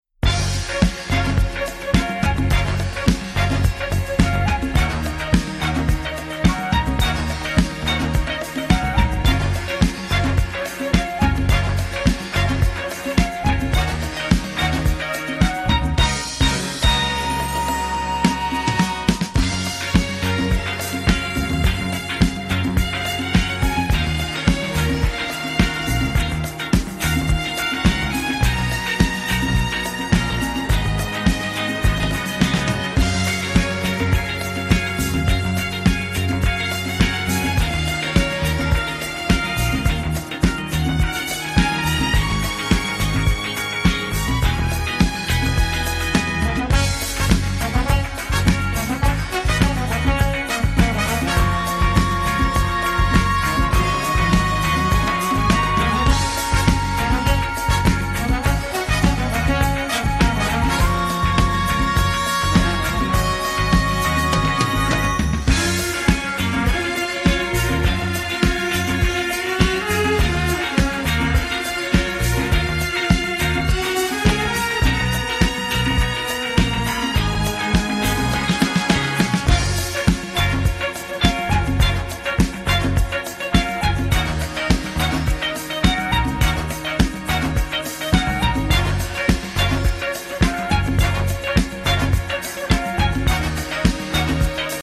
The breezy, mellowed out disco-funk workout
is a deliciously slinky and sophisticated soul strut.